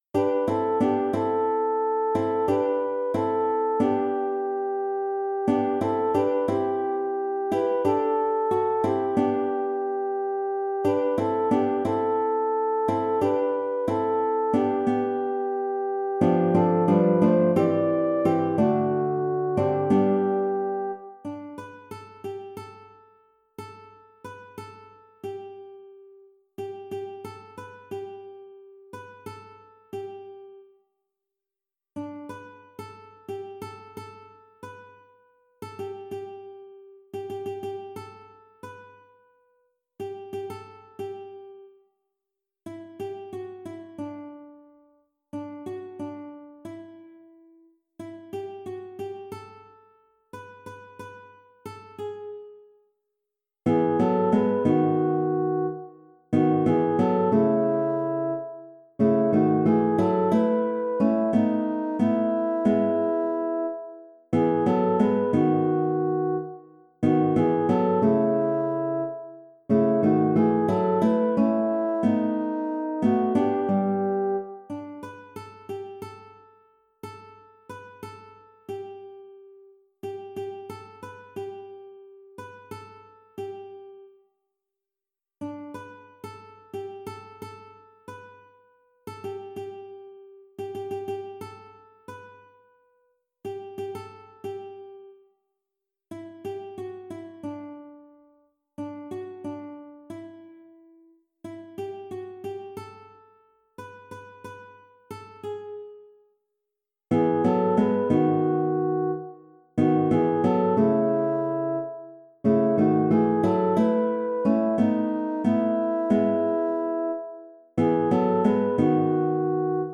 Vart du än går sop